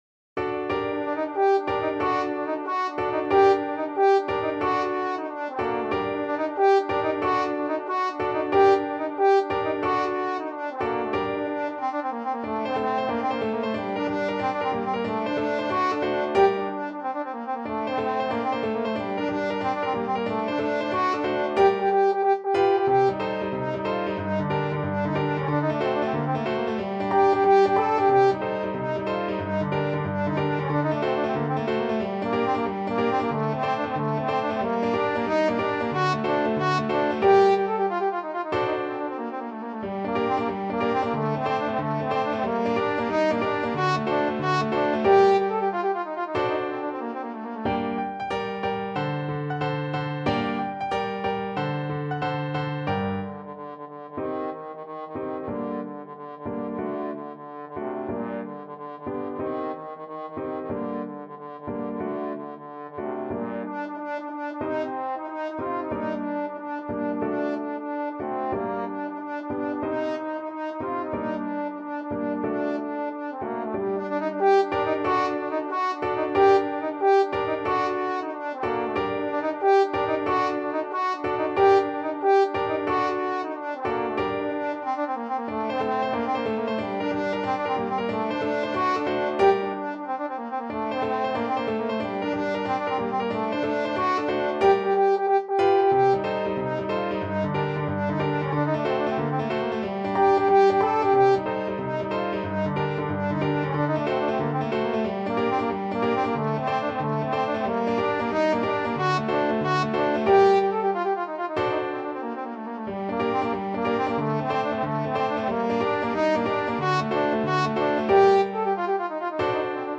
Trombone
This vibrant and lively tradition has a long and fascinating history that dates back centuries. Pizzica music is known for its fast-paced rhythms, intricate melodies, and infectious energy.
G minor (Sounding Pitch) (View more G minor Music for Trombone )
6/8 (View more 6/8 Music)
Molto allegro .=c.184